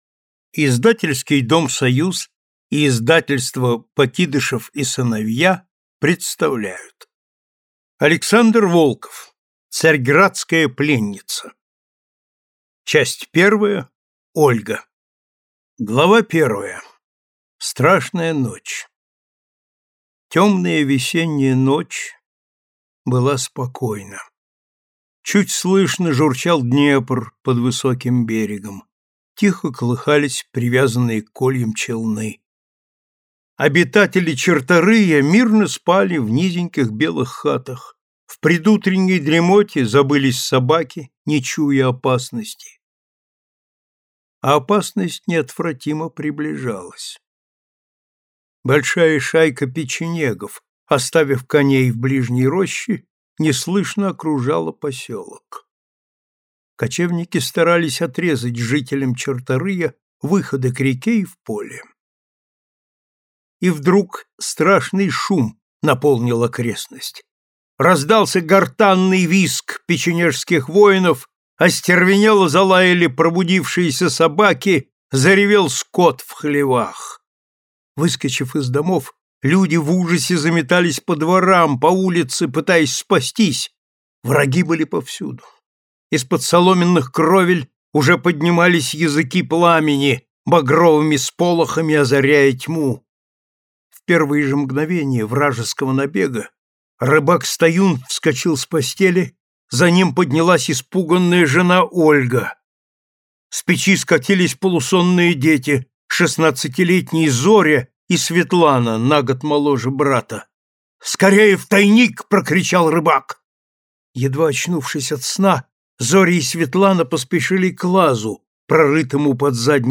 Аудиокнига Царьградская пленница | Библиотека аудиокниг